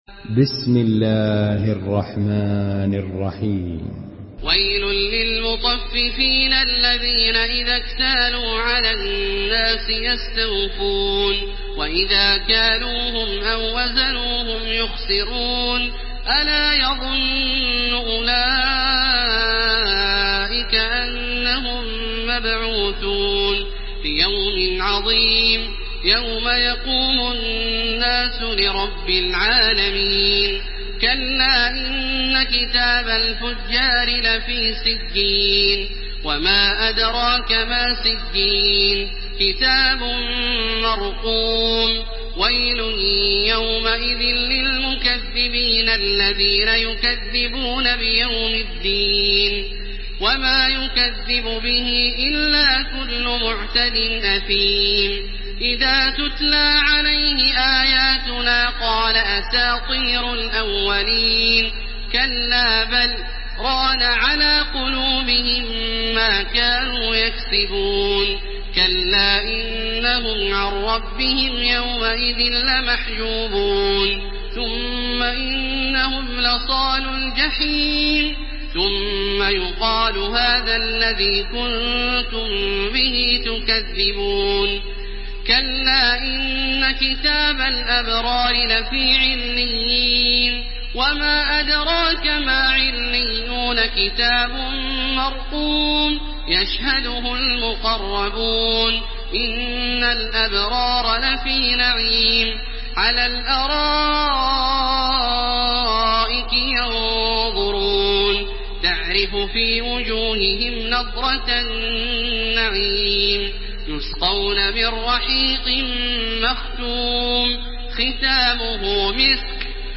Surah Müteffifin MP3 by Makkah Taraweeh 1430 in Hafs An Asim narration.
Murattal Hafs An Asim